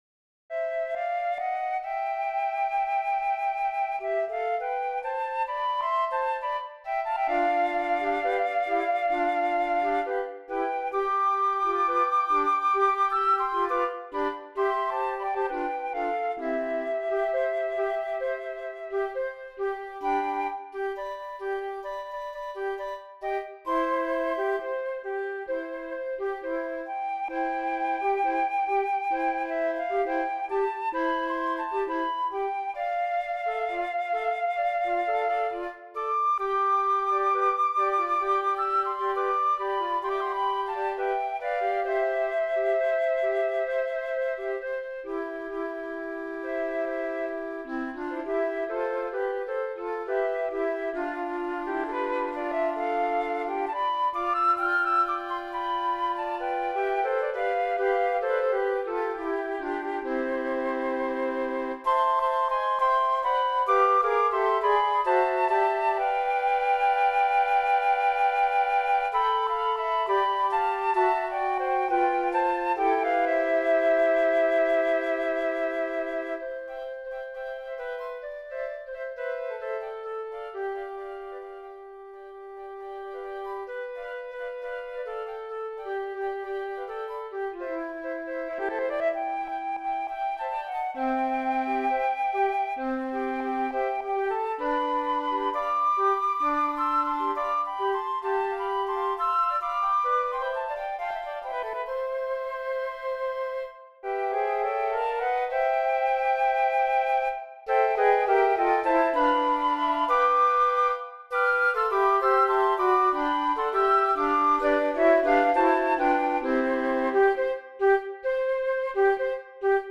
Voicing: Flute Quartet